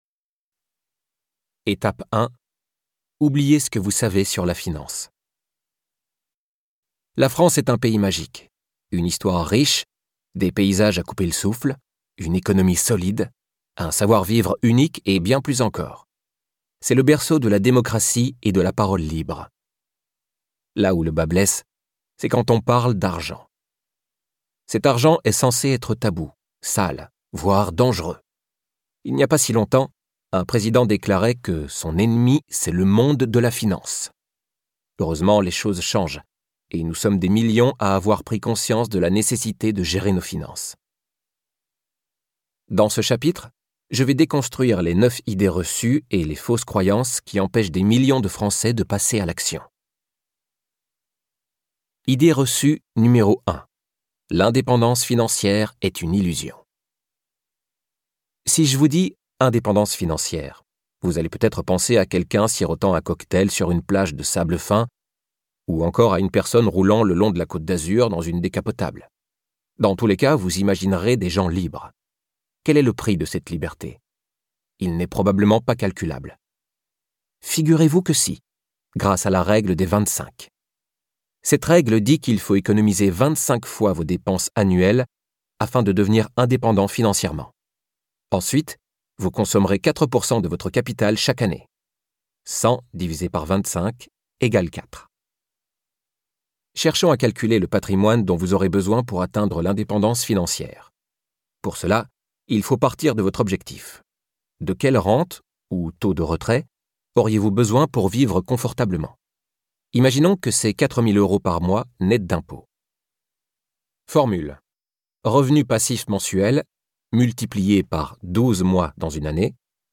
2025-01-30 Peu importe votre âge, les études que vous avez faites, vos connaissances financières ou votre patrimoine actuel : vous pouvez atteindre la liberté financière ! Un ouvrage porté par la lecture dynamique